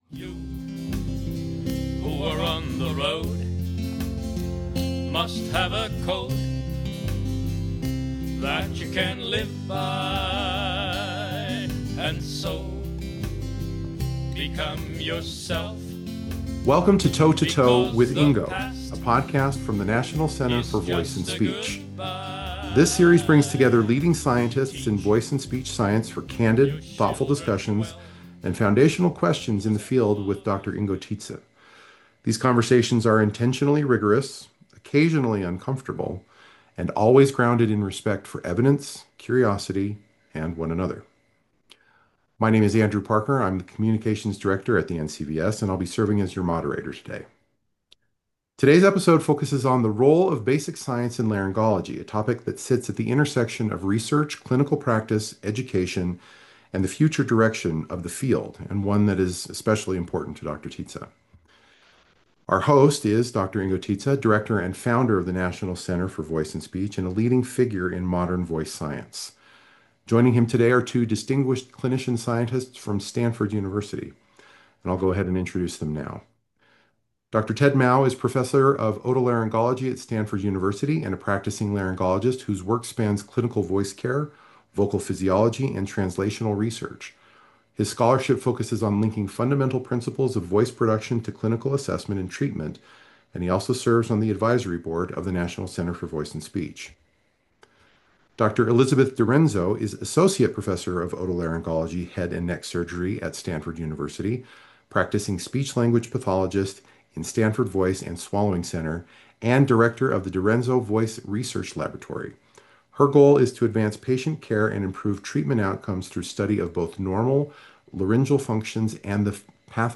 an in-depth conversation on the role of basic science in laryngology